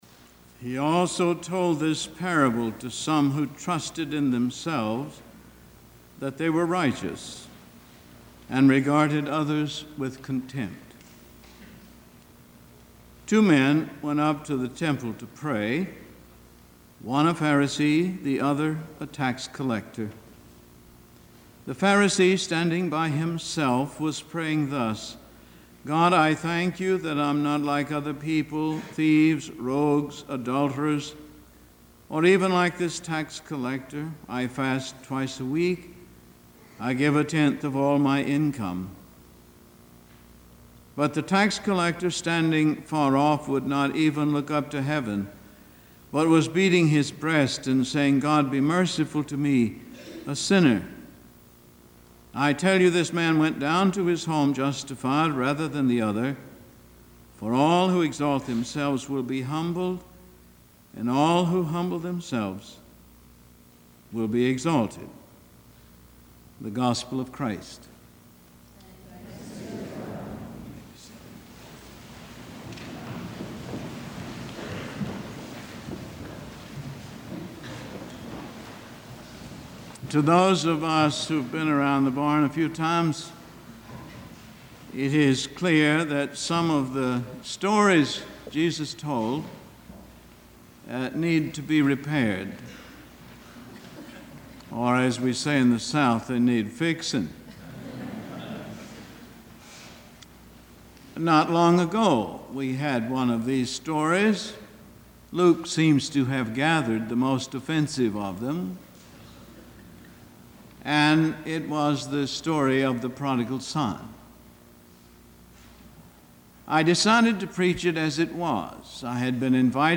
Emory University Worship Service